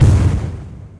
strider_step3.ogg